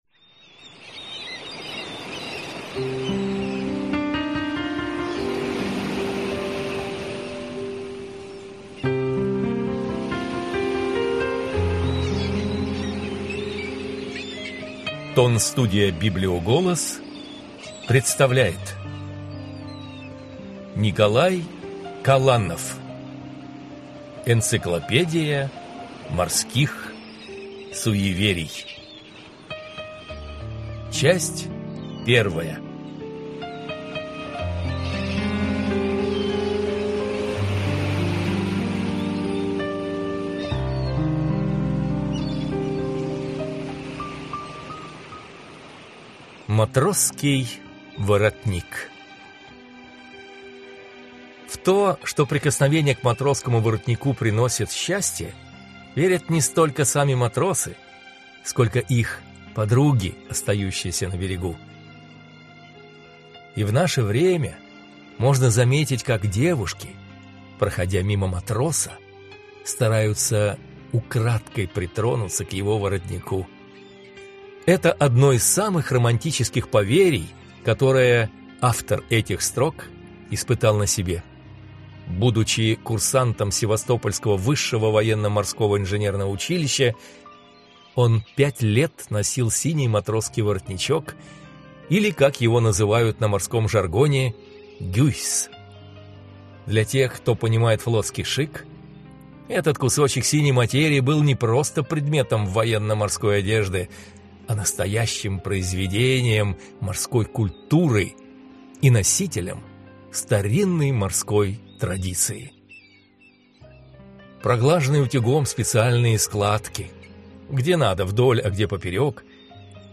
Аудиокнига Энциклопедия морских суеверий. Часть 1 | Библиотека аудиокниг